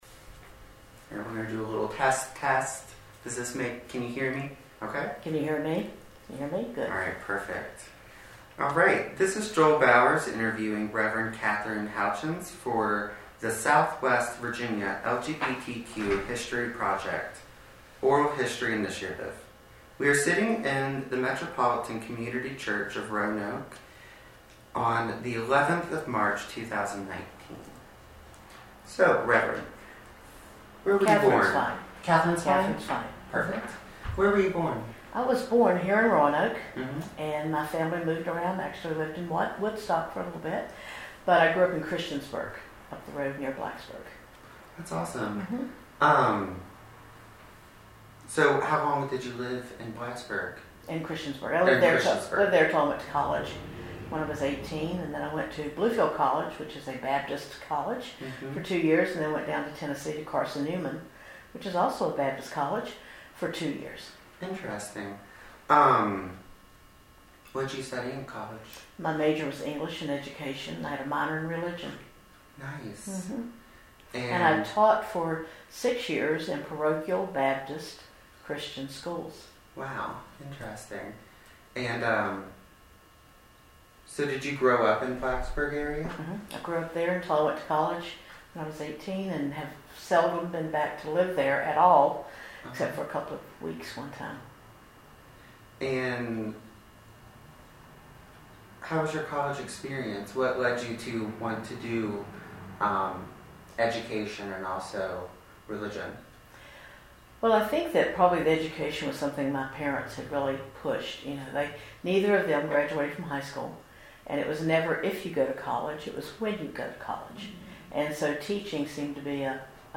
Location: Metropolitan Community Church of the Blue Ridge